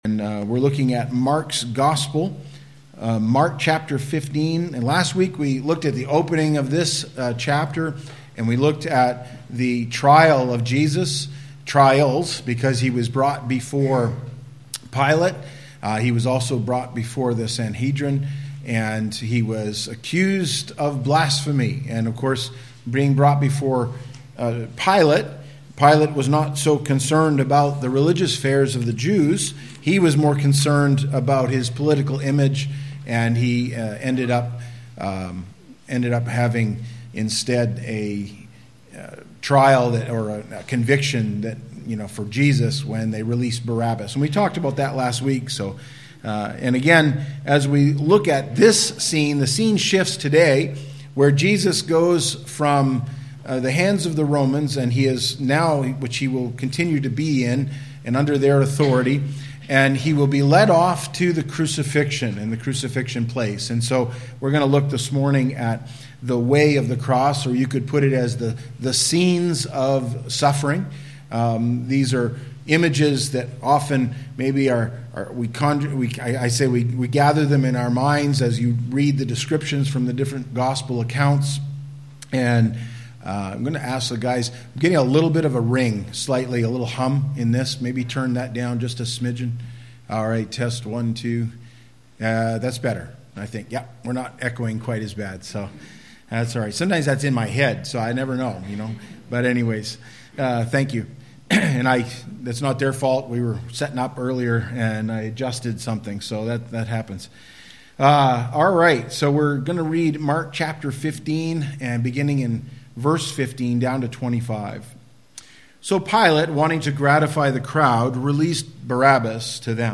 Sermons by Madawaska Gospel Church